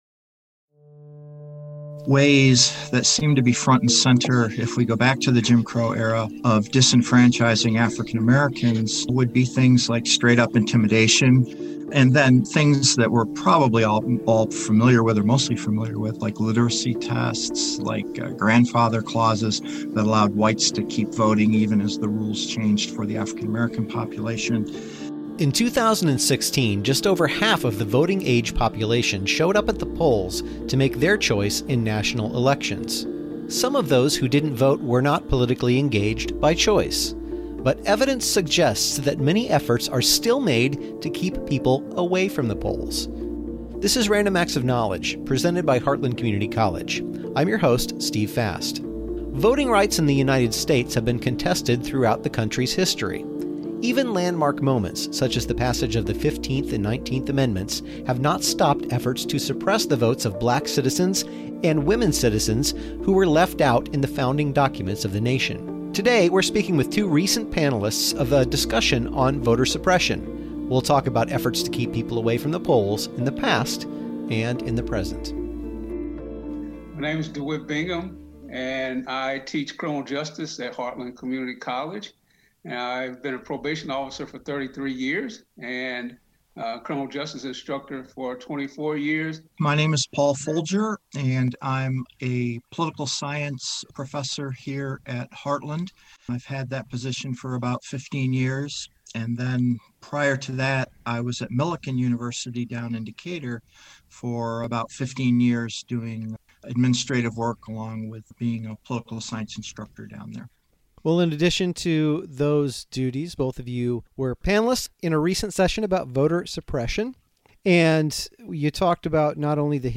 Even after the passage of the 15th and 19th Amendments were passed, efforts were still made to keep black citizens and female citizens away from the polls. Two panelists discuss how modified voter suppression techniques that date back to the Jim Crow era are still being used to keep people from voicing their vote.